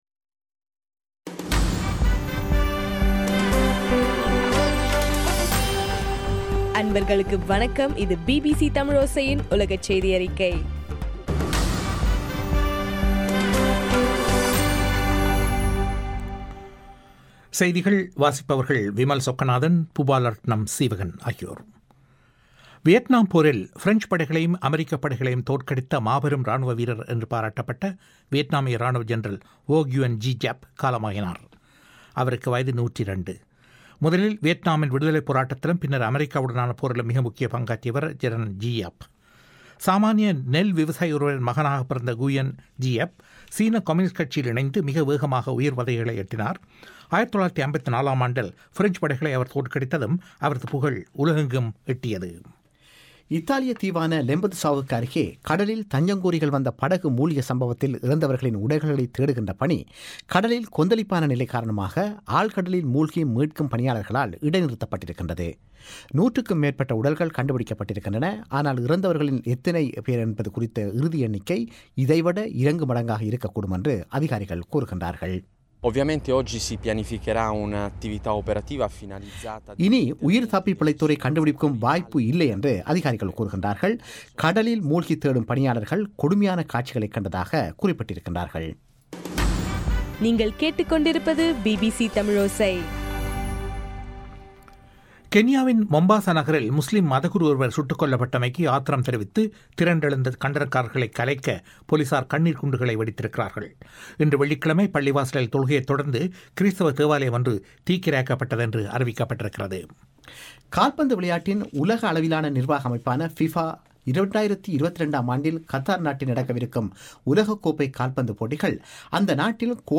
அக்டோபர் 4 2013 பிபிசி தமிழோசையின் உலகச் செய்திகள்